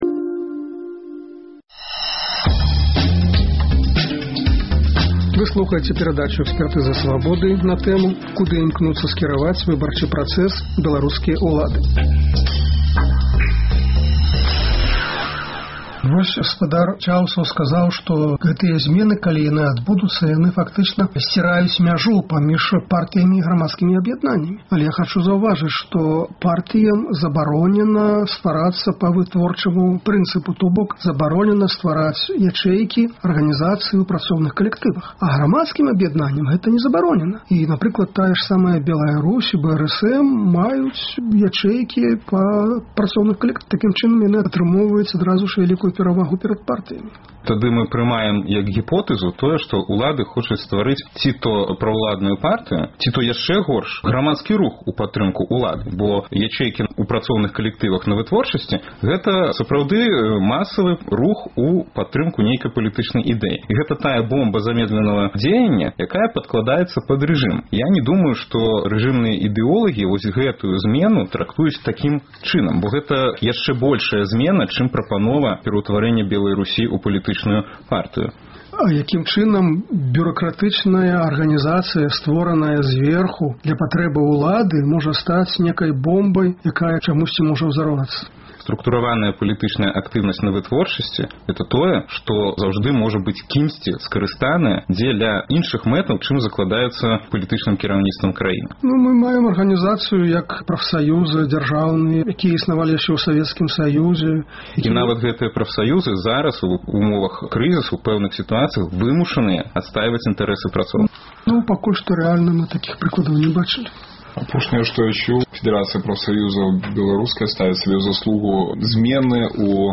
Чаму ўлады спужаліся байкоту? Гэтыя пытаньні абмяркоўваюць за круглым сталом